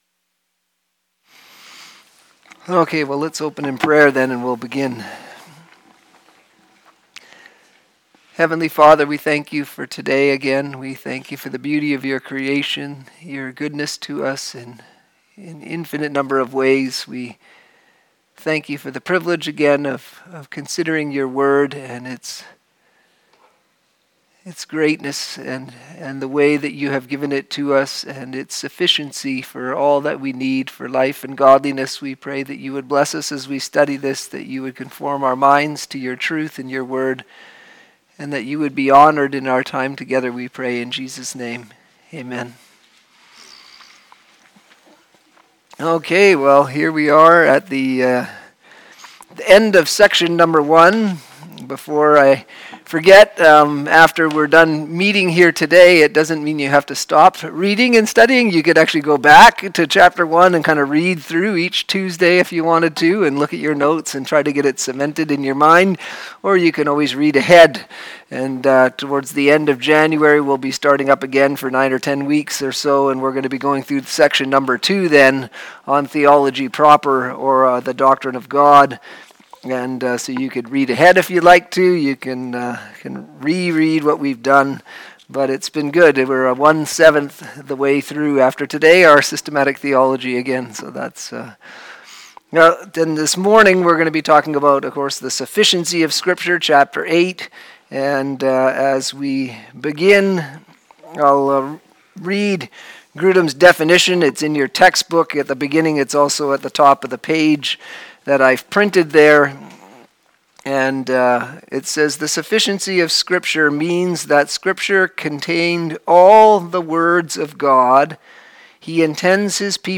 Sermons
Systematic Theology Class - Teaching